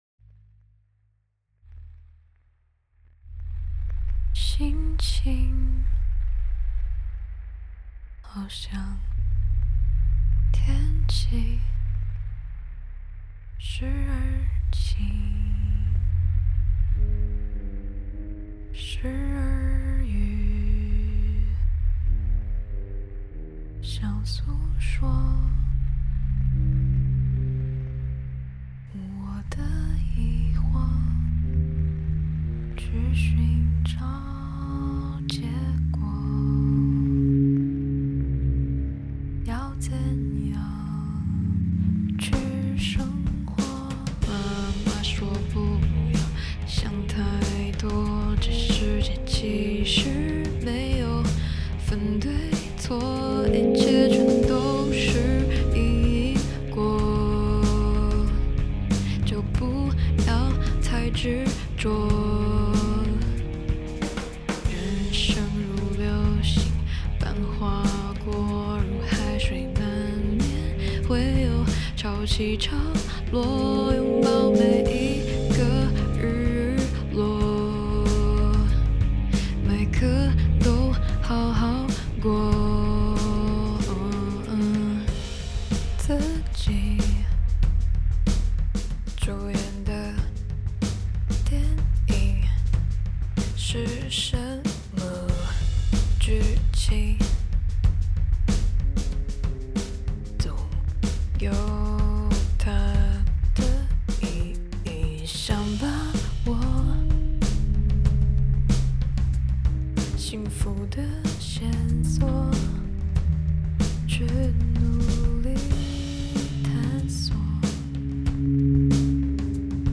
Genre: C-Pop
Version: Demo